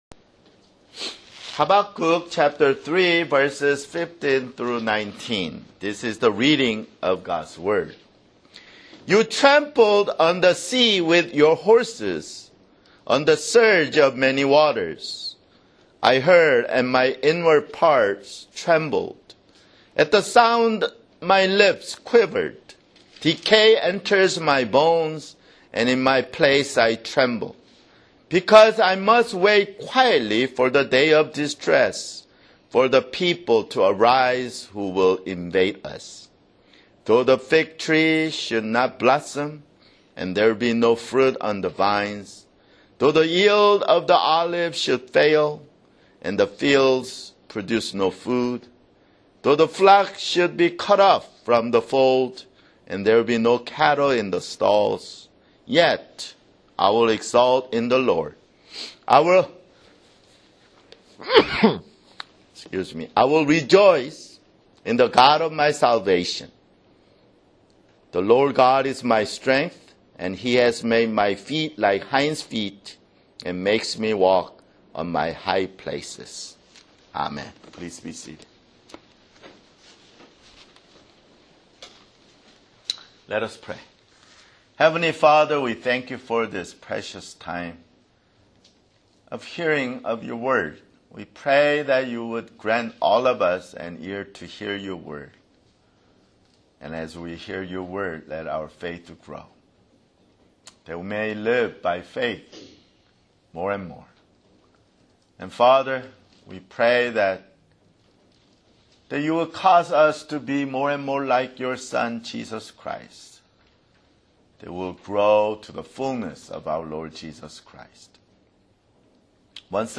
[Sermon] Habakkuk (15)